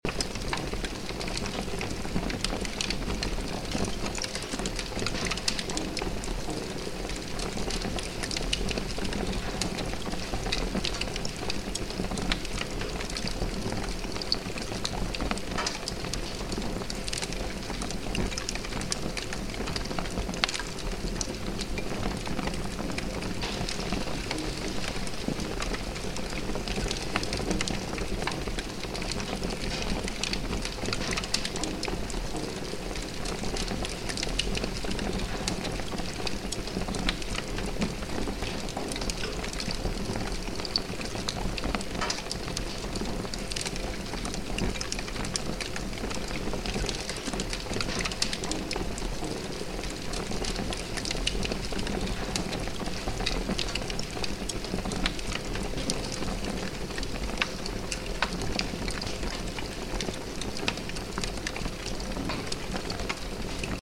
Huge Fire Sound Effect
Huge-fire-sound-effect.mp3